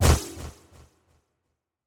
SaninBaseAttack.wav